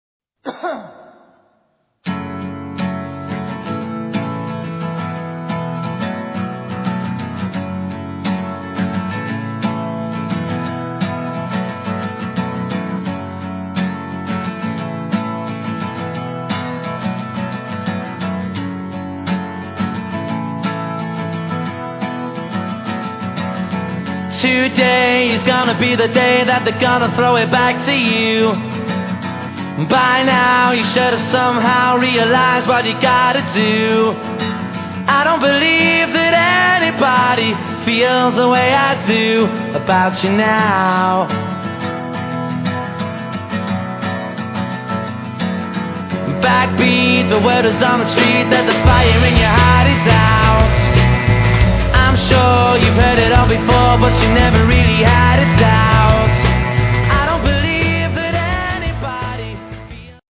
Britpop opsamling